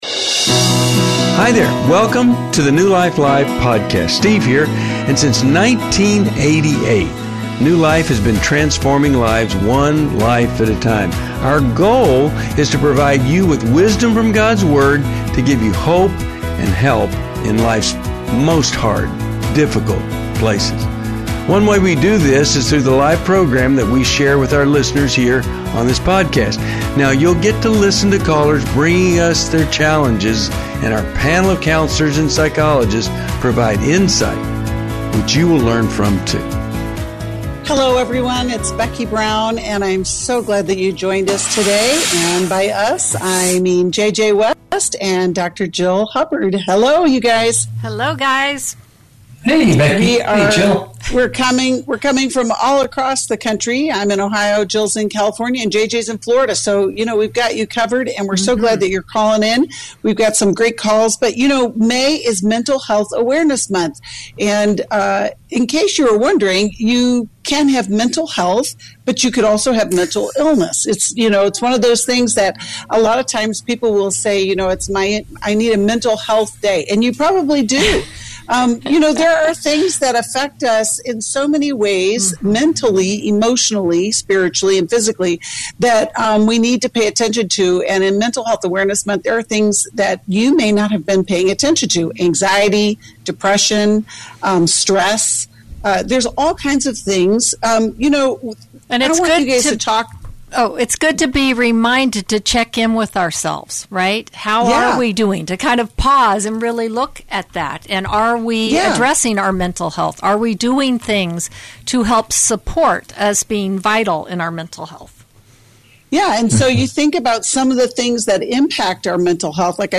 Explore relationships, boundaries, and healing as callers discuss sexual integrity, in-law challenges, and coping with loss on New Life Live: May 11, 2023.